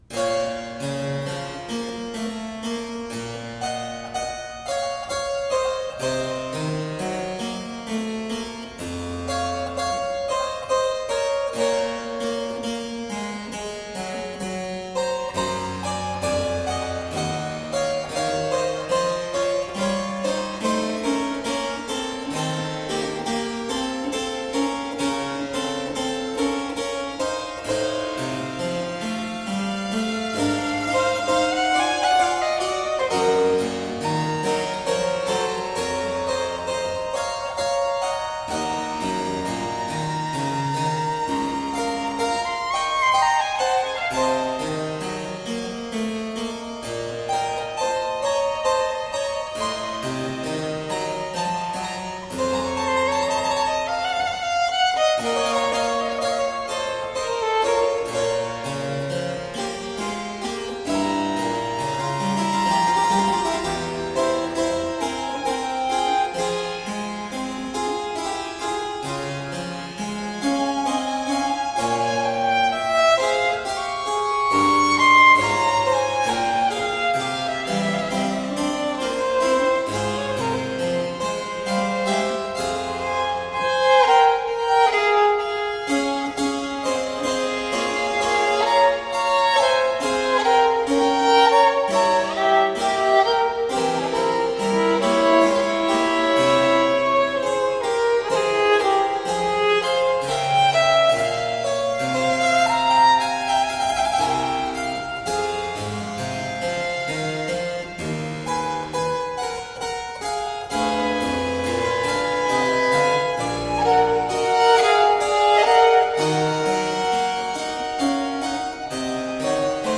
Baroque violin & harpsichord.